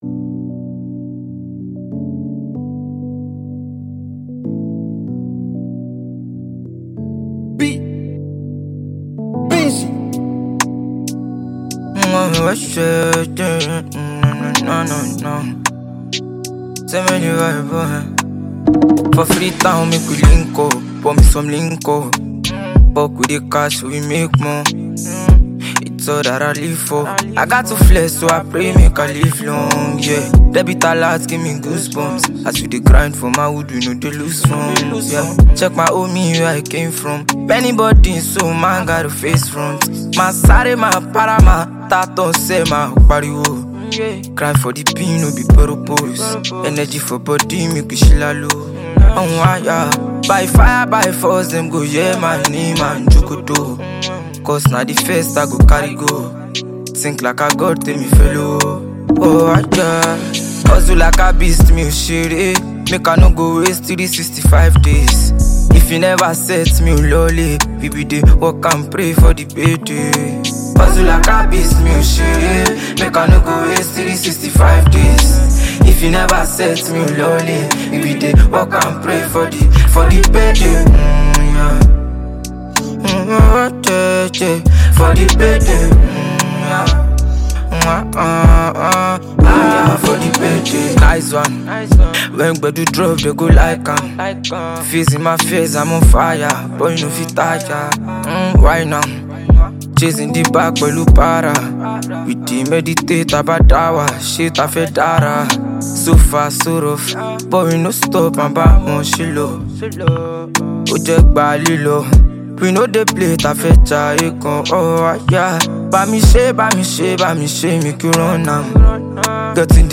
Fast-rising Nigerian singer
energetic new single